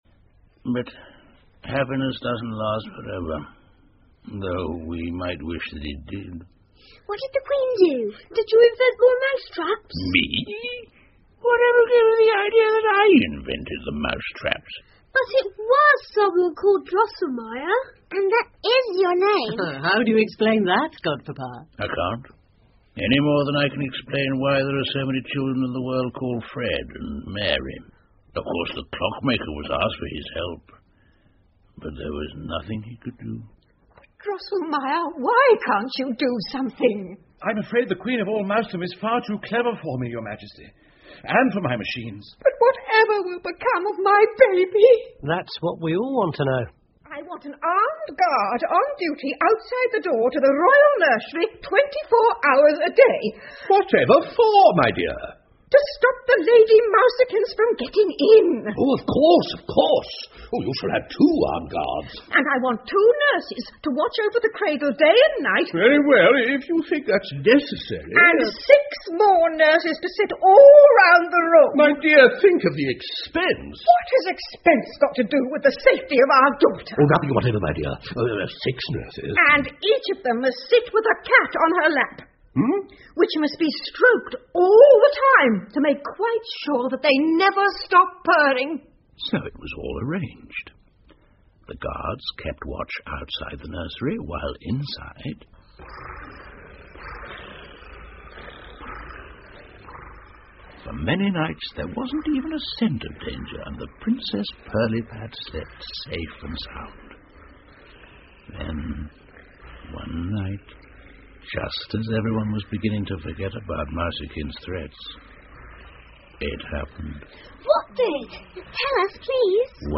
胡桃夹子和老鼠国王 The Nutcracker and the Mouse King 儿童广播剧 18 听力文件下载—在线英语听力室